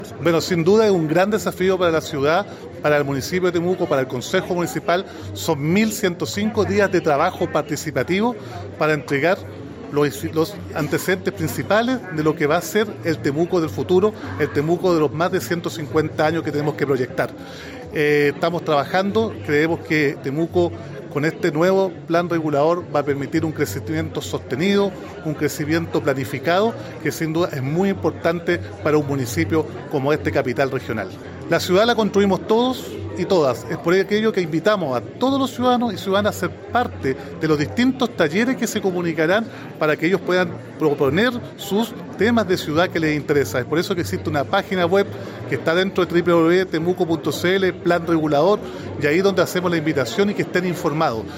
La actividad, realizada en la sala de Concejo de la Municipalidad de Temuco, reunió a autoridades comunales, regionales, ancestrales, dirigentes y dirigentas sociales, además de representantes del mundo público y privado.
Roberto-Neira-alcalde-de-Temuco.mp3